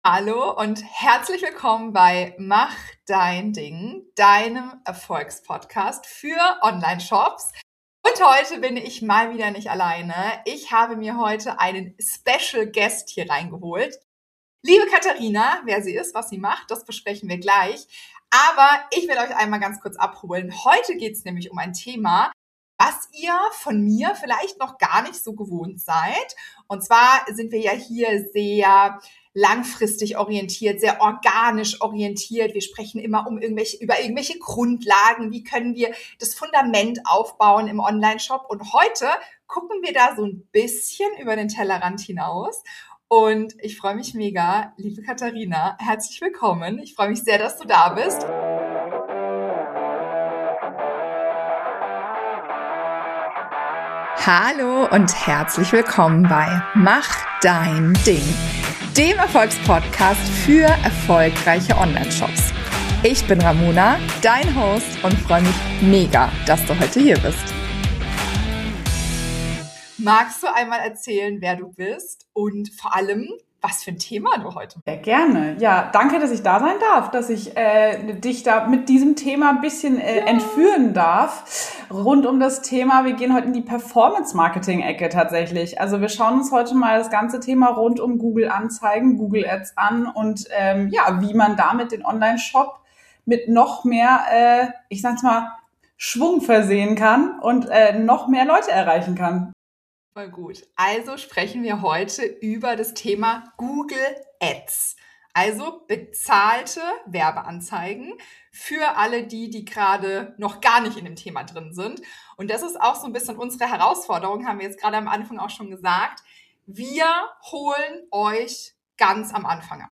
Beschreibung vor 5 Tagen In dieser Folge von „Mach dein Ding" hole ich mir zum ersten Mal eine echte Google-Ads-Expertin ins Studio